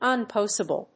/ʌnˈpɒsɪbəl(米国英語)/